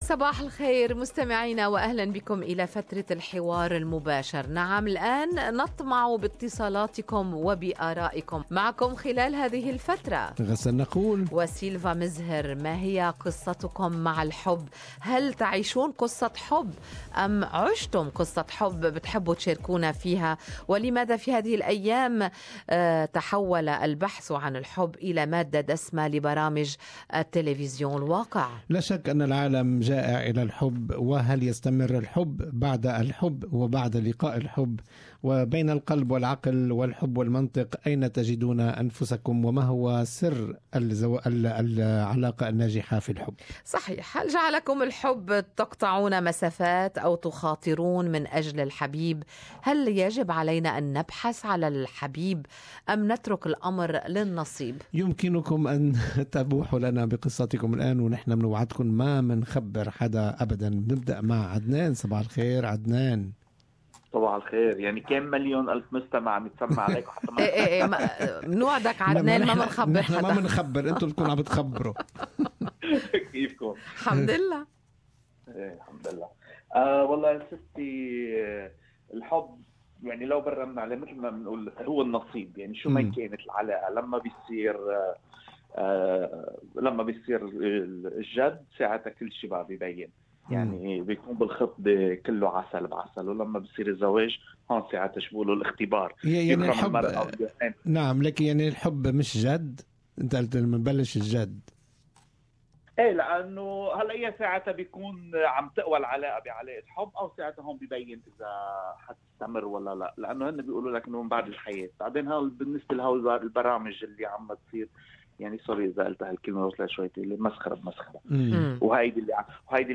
Talkback: Share your love story